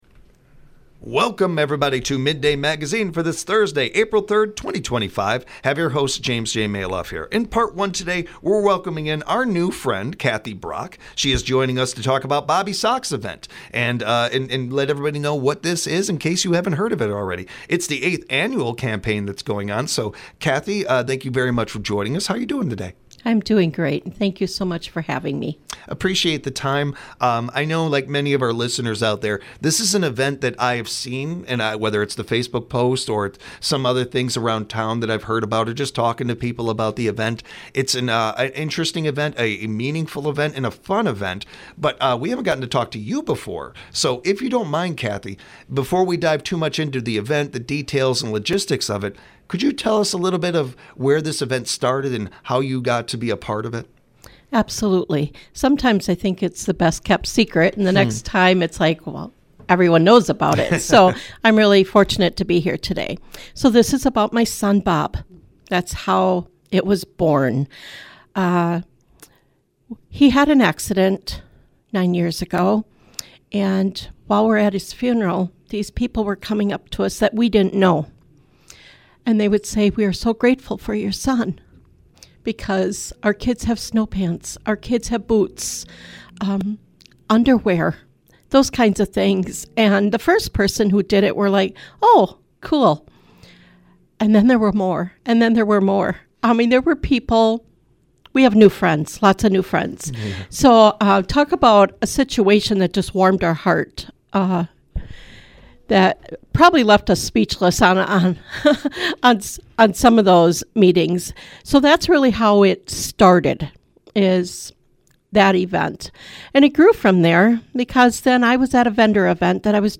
92.7 WMDX